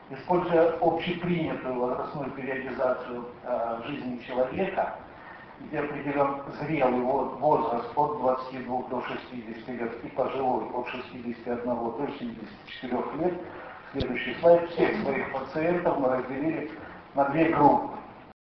Съезд Ассоциации Урологов Дона с международным участием. Ростов-на-Дону, 27-28 октября 2004 года.
Лекция: "Цистэктомия и деривация мочи у пожилых пациентов при раке мочевого пузыря: эффективность и безопасность".